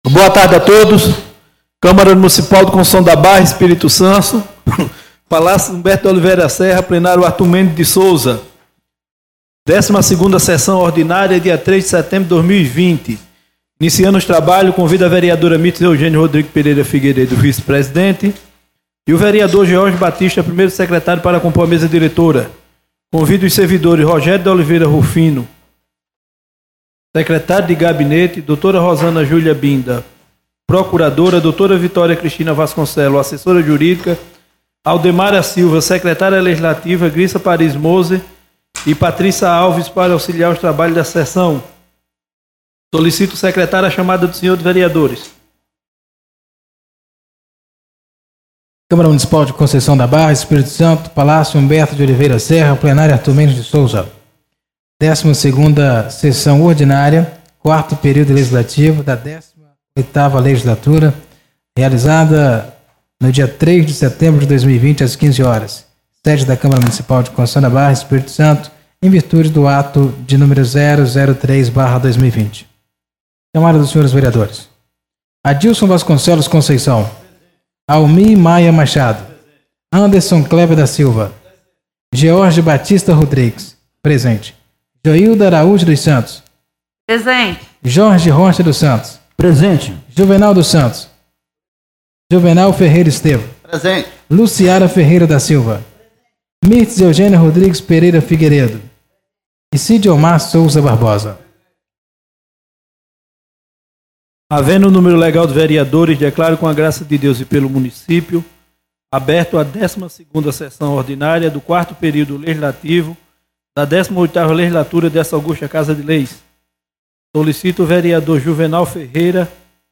12ª Sessão Ordinária do dia 05 de Setembro de 2020